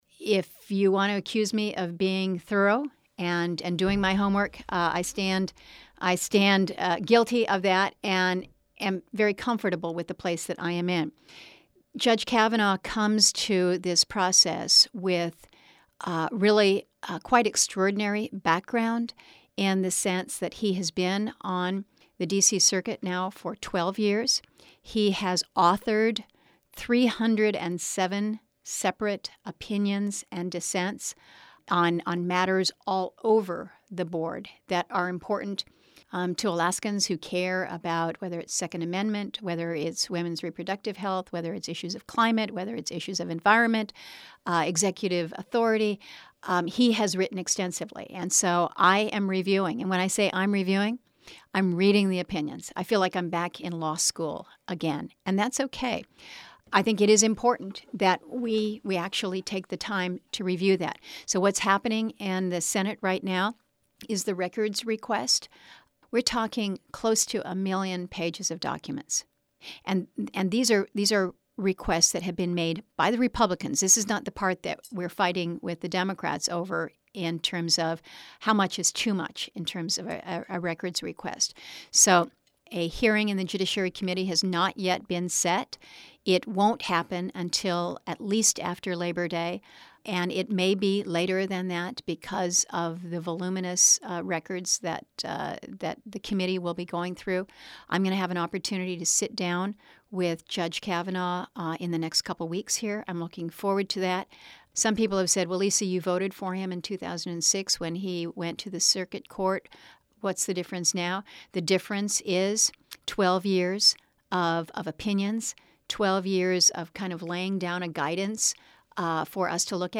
Below is an edited seven-minute version of the interview: